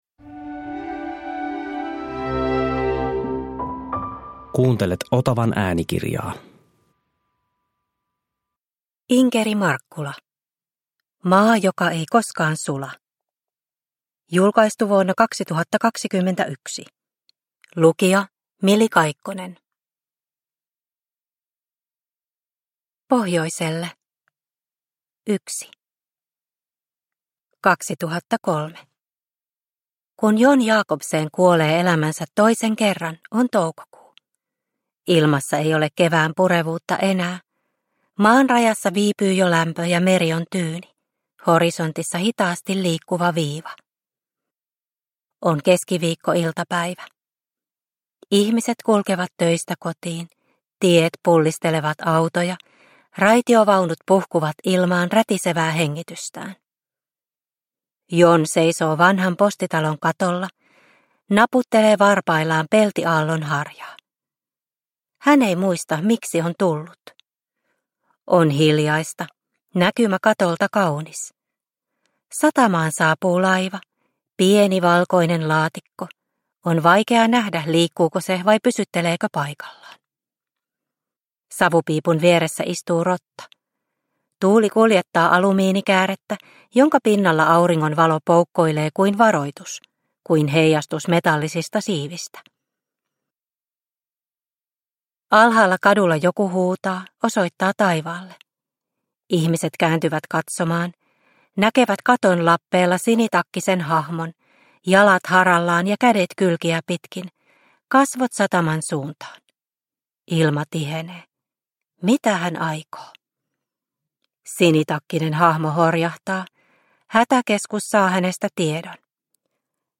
Maa joka ei koskaan sula – Ljudbok – Laddas ner